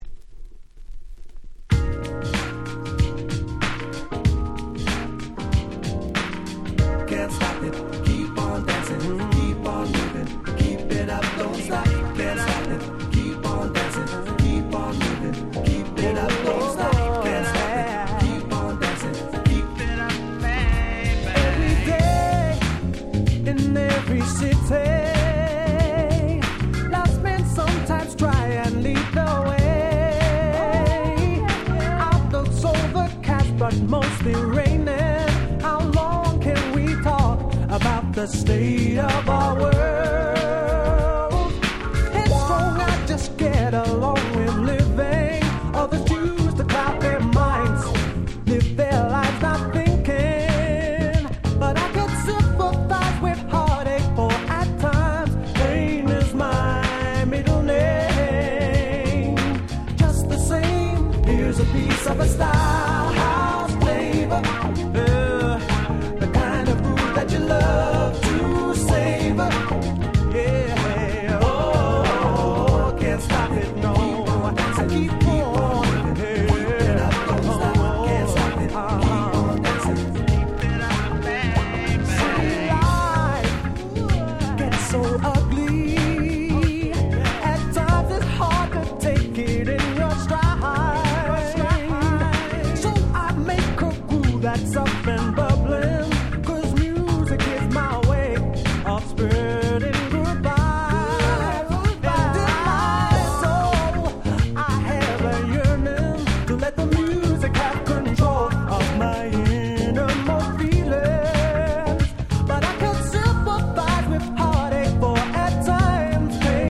96’ Super Nice UK Street Soul / R&B Compilation !!